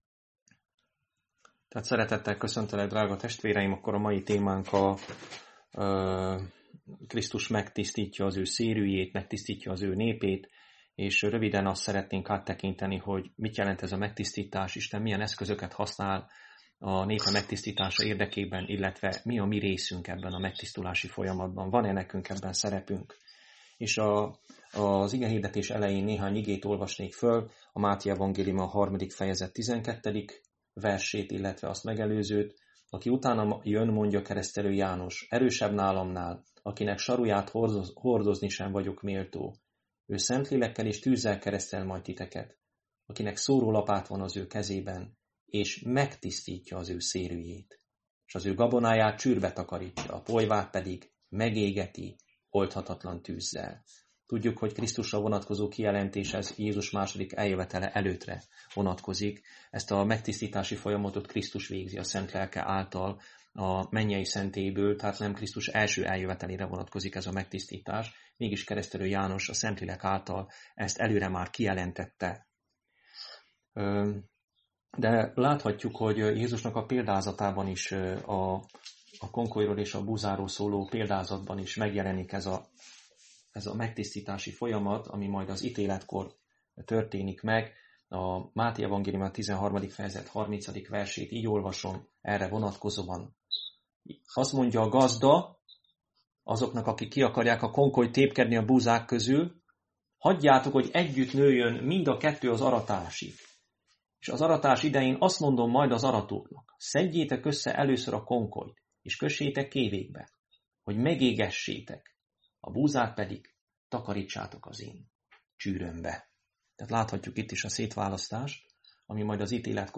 Krisztus megtisztítja az Ő népét Igehirdetések mp3 Link az igehirdetéshez Hasonló bejegyzések Igehirdetések mp3 Ébredj fel a lelki halálból és felragyog neked...